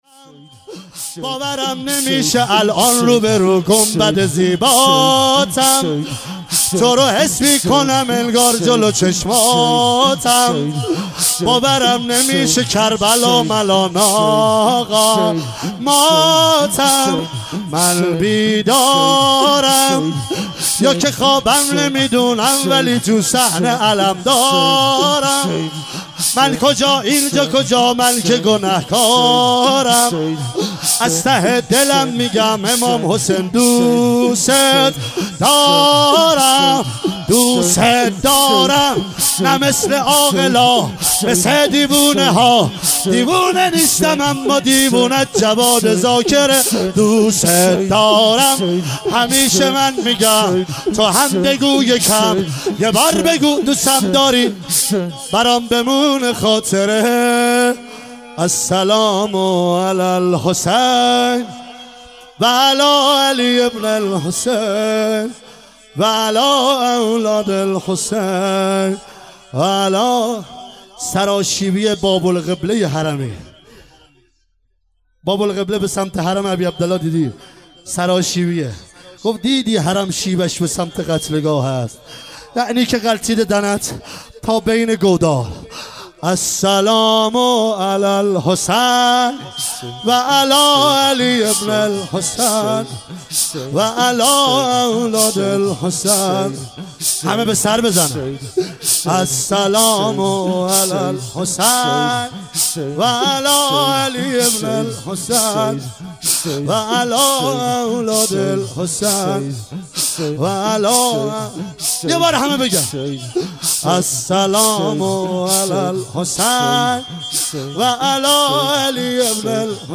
شب دوم محرم 1396
شور